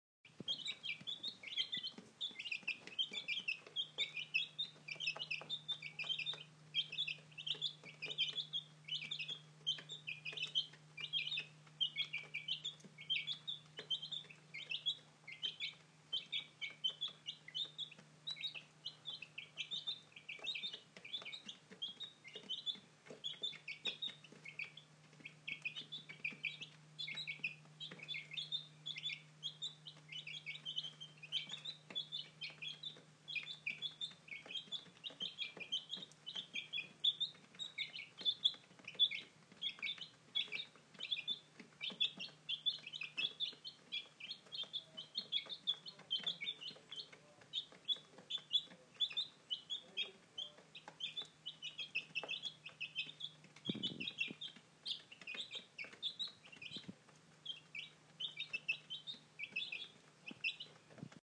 Chirping Baby Chicks